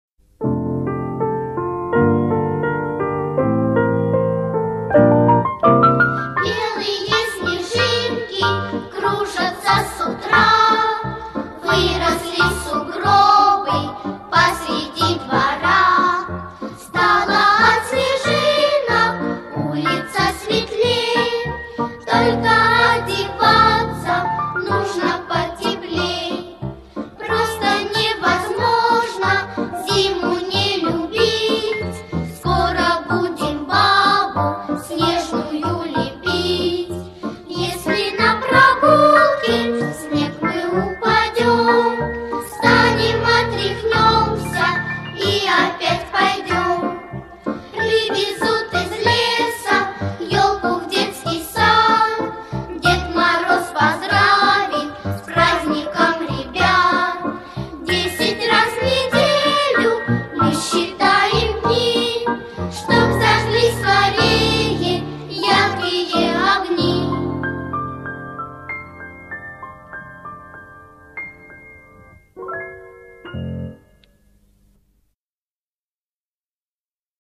Детская Новогодняя песенка - Белые снежинки ..mp3